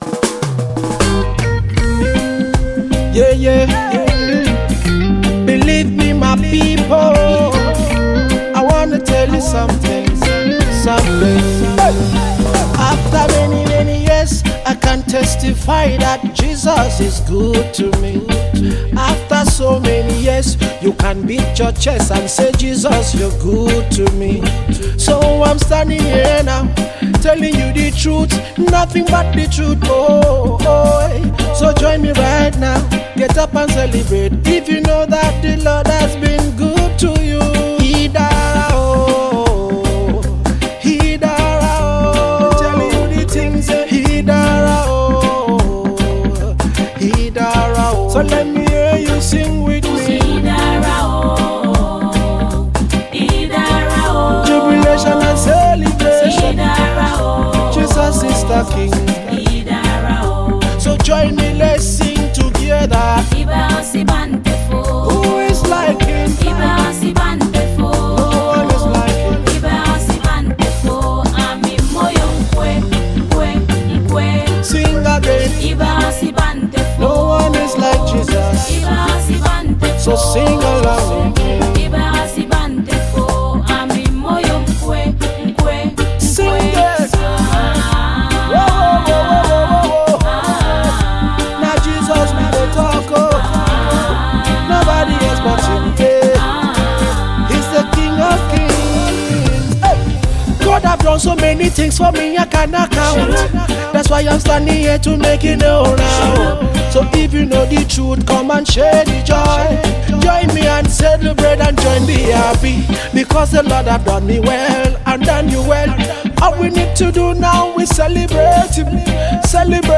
Reggae singer
inspirational single
” the energetic singer said